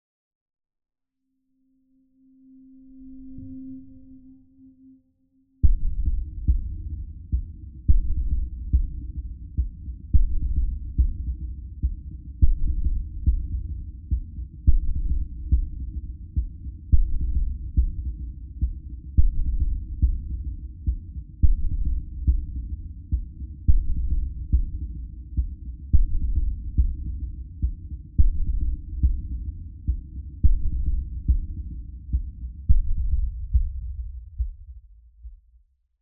STest1_200Hz.flac